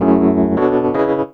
12CHORD01 -L.wav